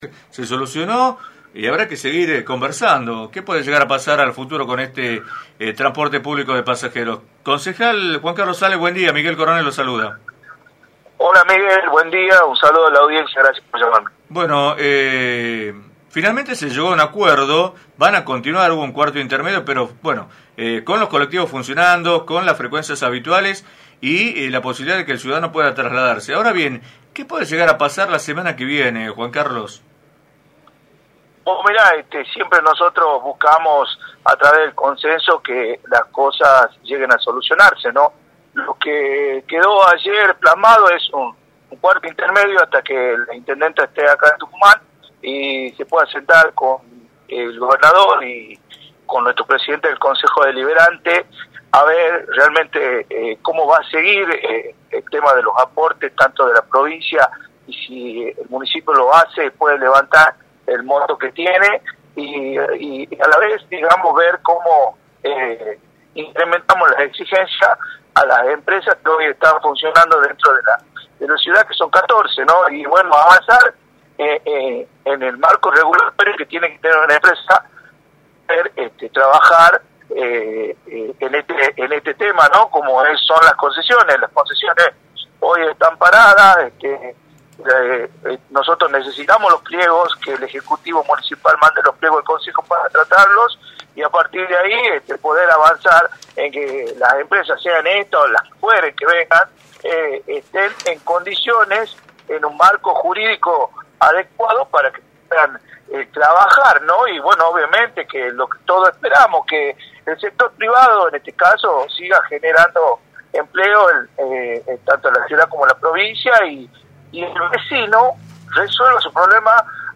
El Concejal Carlos Ale, Vicepresidente 1° del HCD de San Miguel de Tucumán, indicó en entrevista para “6AM” cuál es su postura con respecto a la crisis que atraviesa el transporte público de pasajeros en Tucumán.